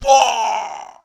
EnemyKnockDown1.wav